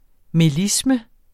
Udtale [ meˈlismə ]